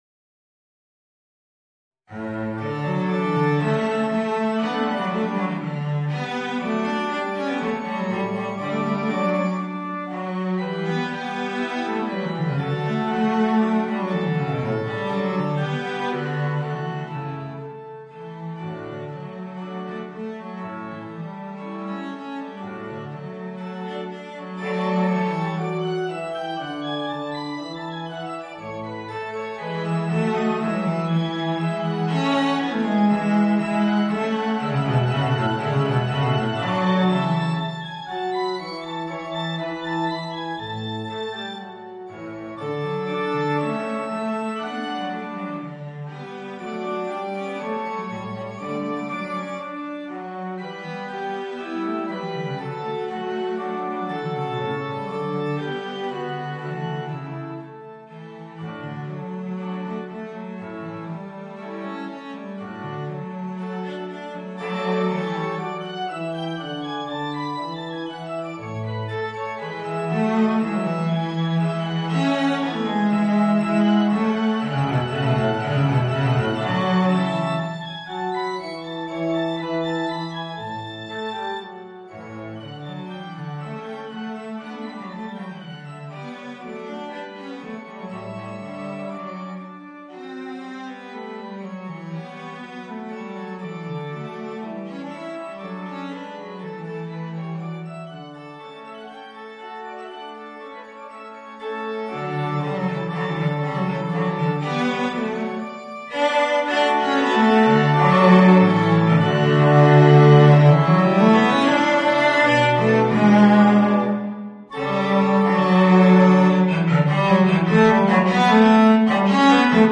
Voicing: Violoncello and Organ